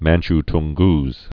(mănch-tng-gz, -tŭn-, măn-ch-)